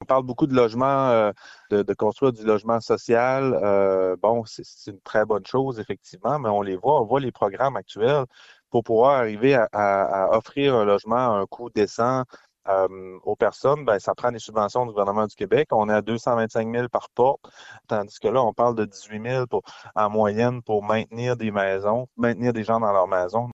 Mathieu Lapointe ne comprend pas cette orientation qu’il qualifie d’économie de bouts de chandelle effectuée aux frais des plus vulnérables pendant que le gouvernement dit souhaiter garder les gens dans leur résidence dans un souci de saines gestion des finances publiques :